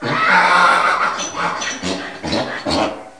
Sound Effects for Windows
donkey.mp3